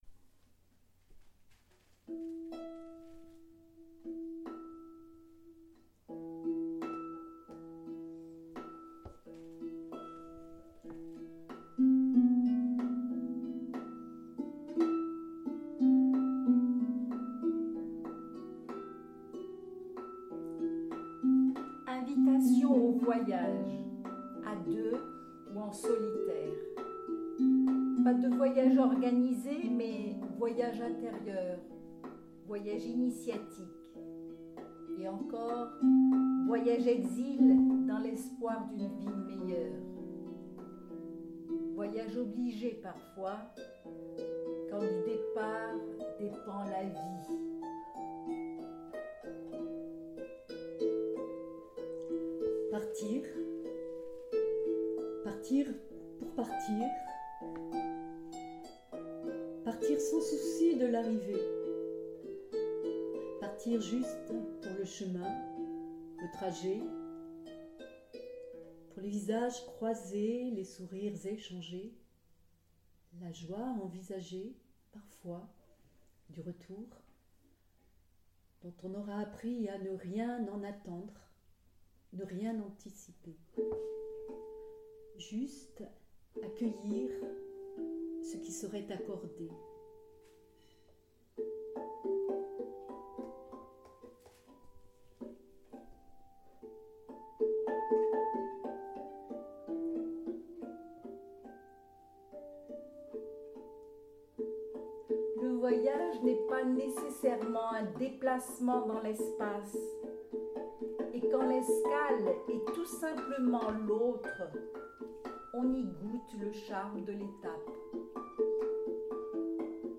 Music reading